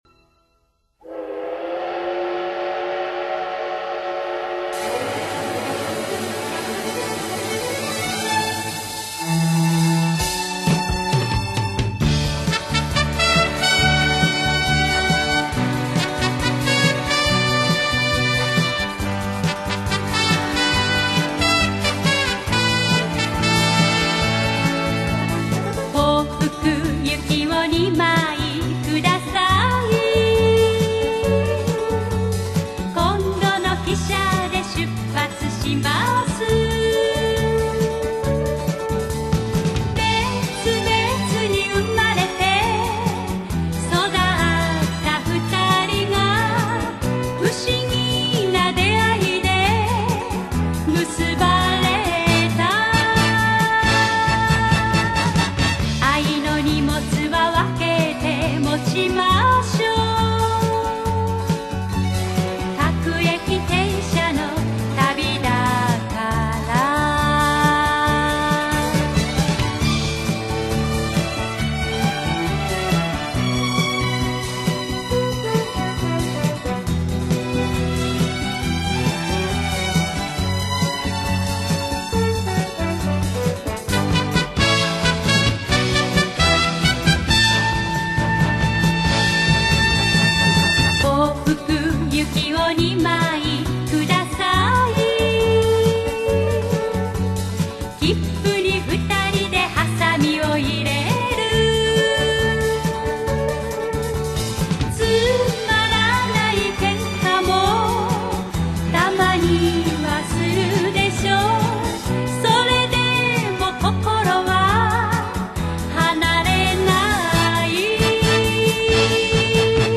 駅にまつわる歌謡曲